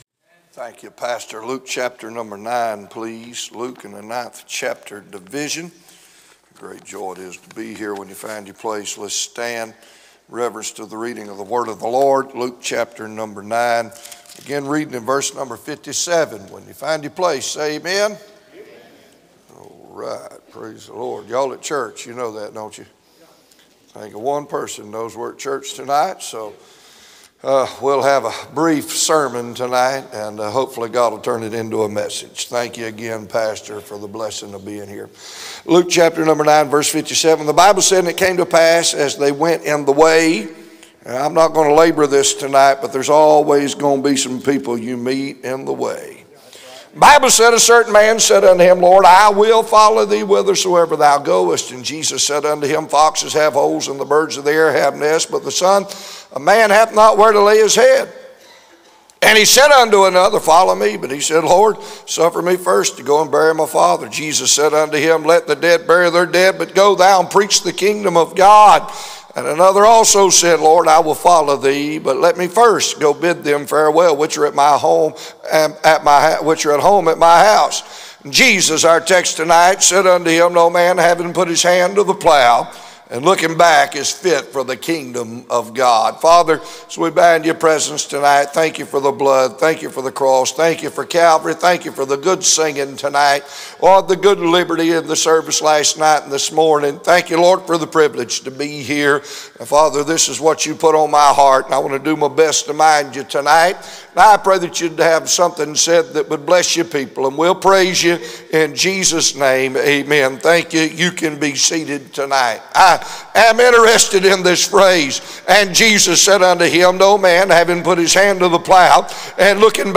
A sermon preached Sunday Evening during our Spring Jubilee, on March 23, 2025.